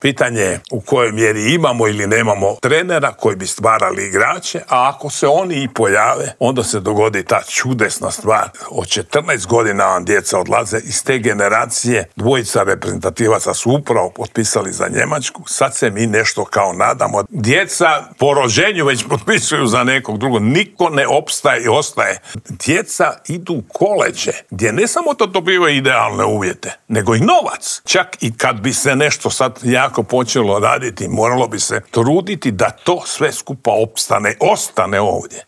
Kako sve krenulo prema dolje, pokušali smo odgonetnuti u Intervjuu tjedna Media servisa u kojem je gostovao bivši TV komentator, legendarni Slavko Cvitković.